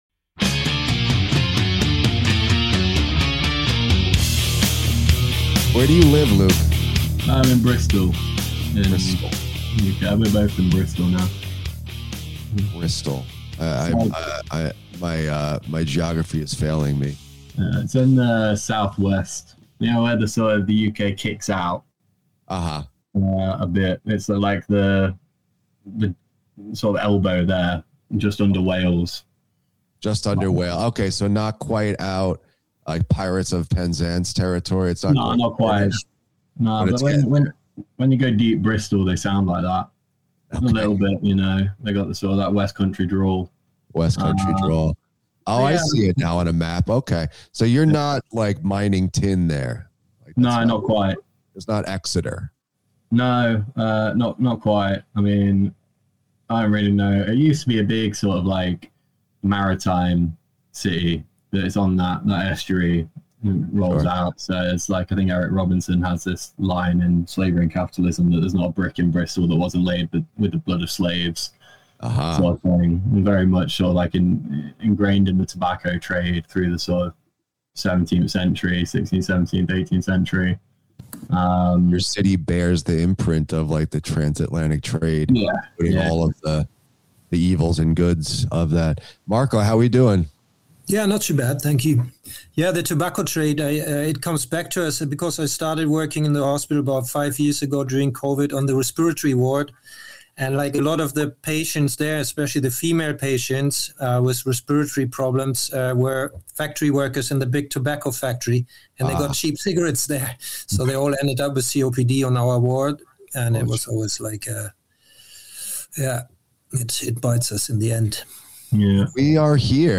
This is a wide-ranging and fascinating discussion with fellow workers who are grappling with the biggest questions facing our class at the moment.